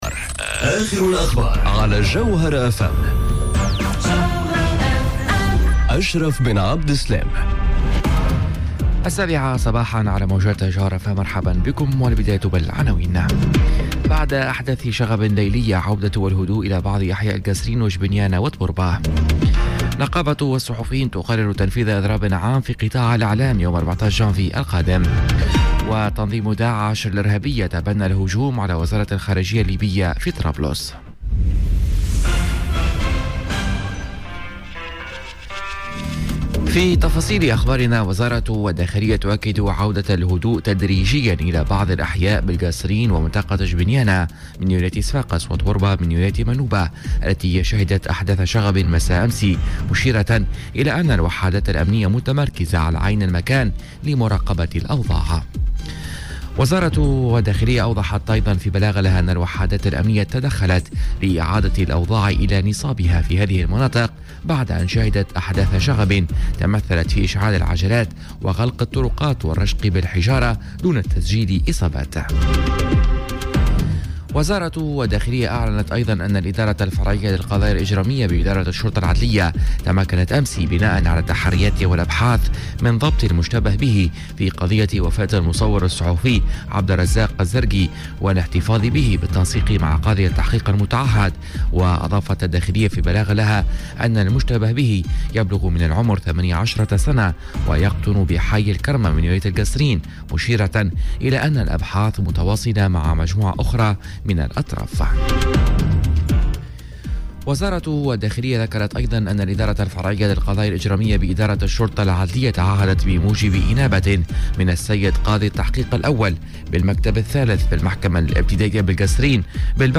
نشرة أخبار السابعة صباحا ليوم الإربعاء 26 ديسمبر 2018